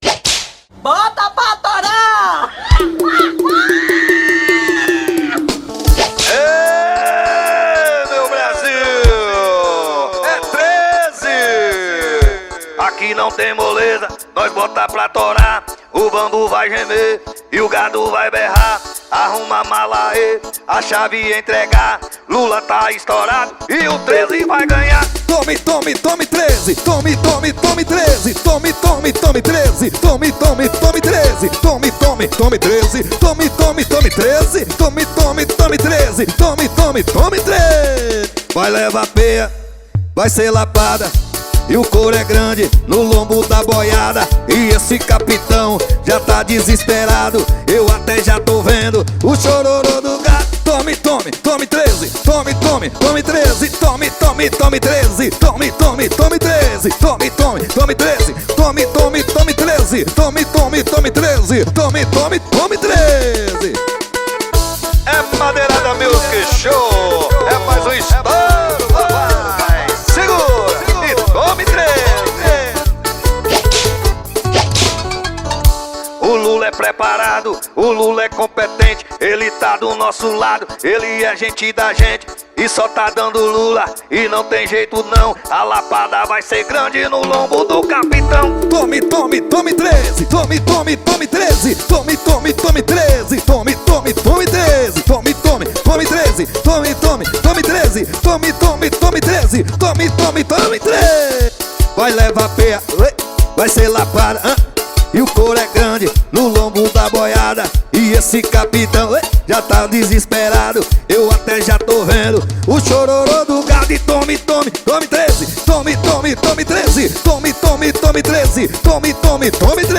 2025-01-06 00:30:16 Gênero: Axé Views